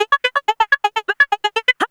Index of /90_sSampleCDs/Houseworx/12 Vocals/74 Processed Vocal Loops